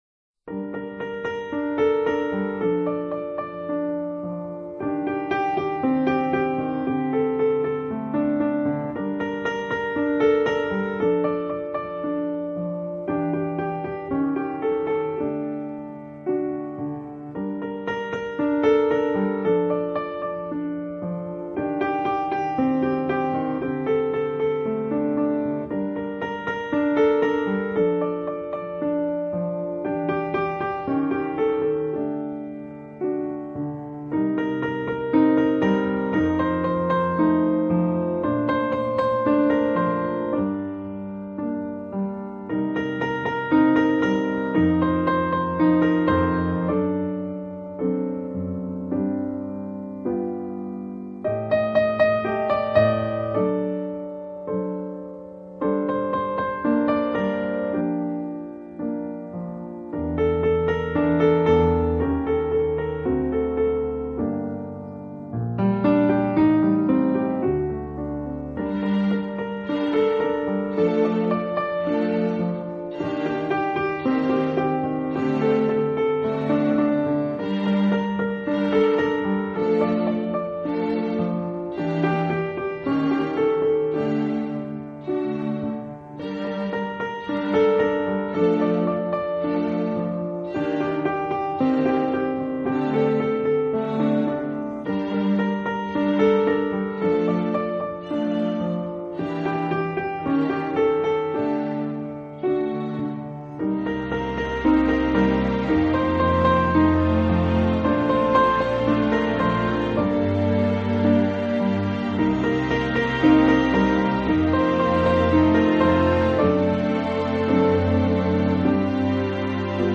【钢琴】
【钢琴纯乐】